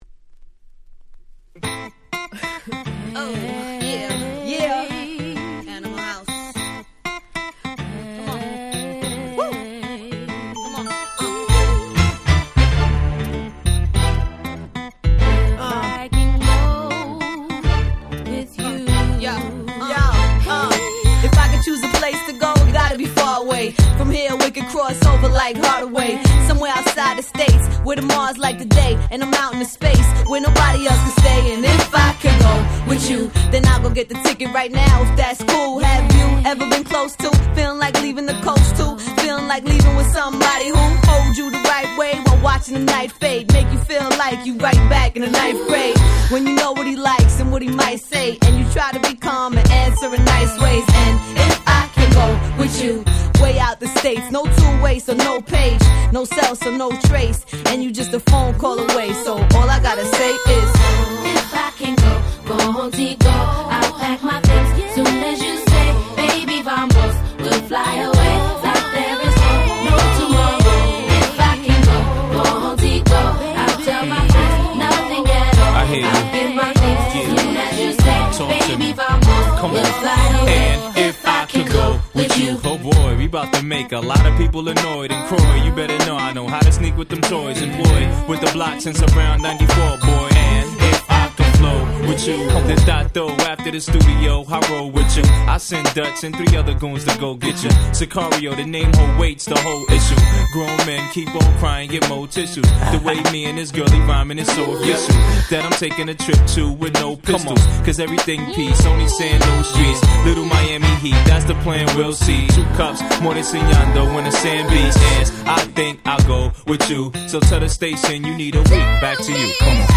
02' Super Hit Hip Hop !!